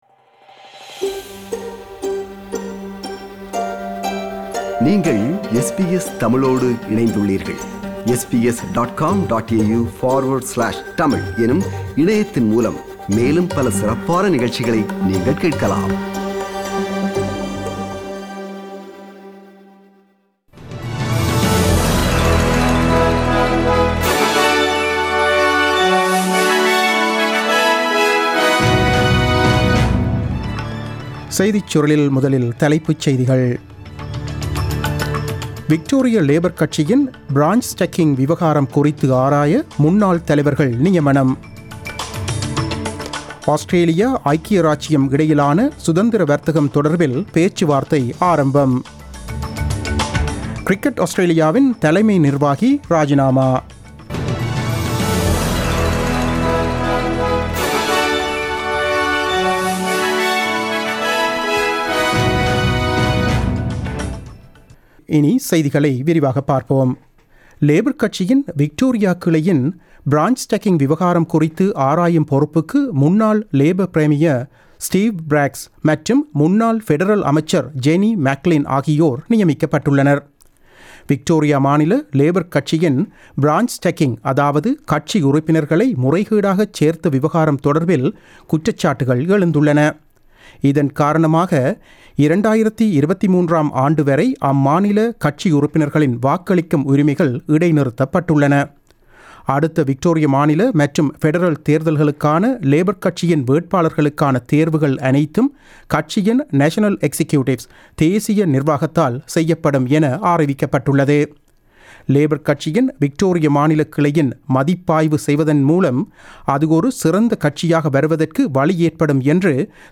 The news bulletin broadcasted on 17 June 2020 at 8pm.